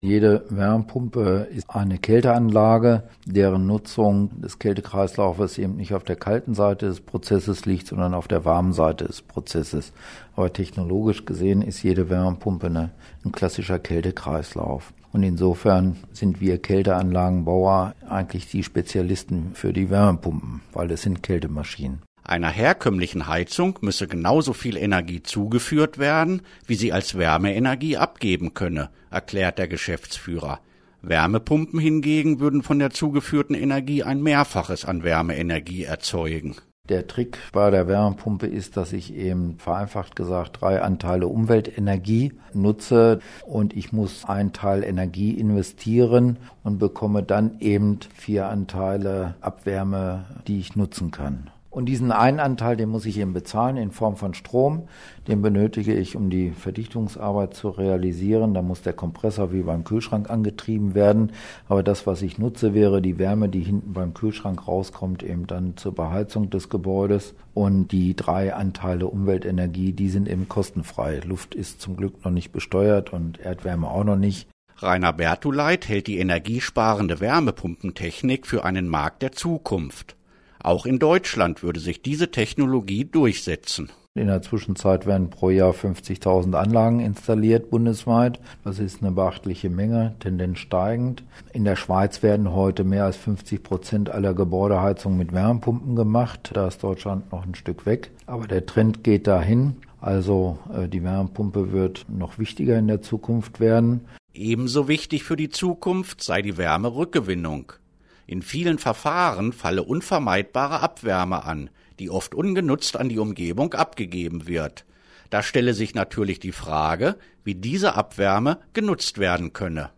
Förderungen für den Heizungstausch Alle Infos des BMWK auf einen Blick Radiobeitrag Wärmepumpen Informieren Sie sich in dem Interview von Radio Aktiv mit uns über verschiedene Wärmepumpentypen, Einsatzmöglichkeiten und Kosten. Den gesamten Beitrag hören Sie unter folgendem Link: Interview Wärmepumpen